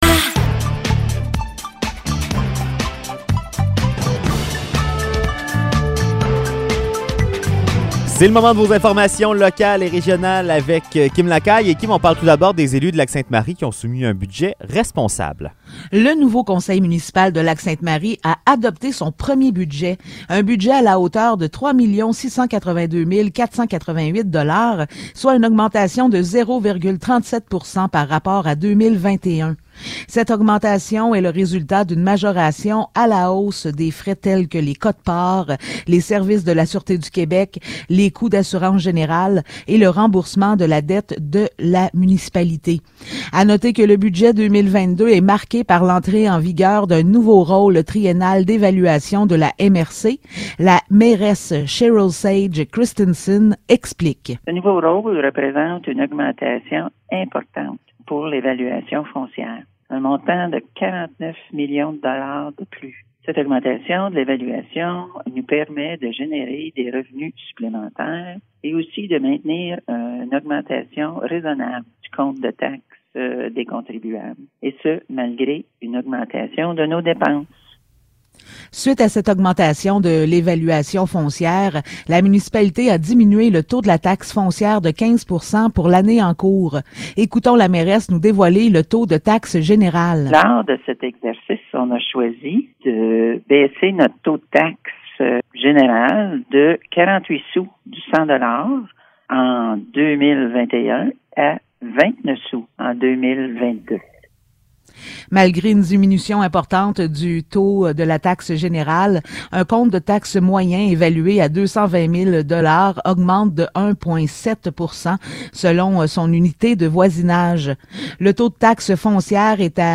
Nouvelles locales - 25 février 2022 - 16 h